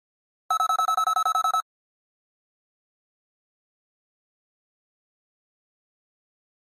Flash Alarm High Frequency Electronic Pulse Alarm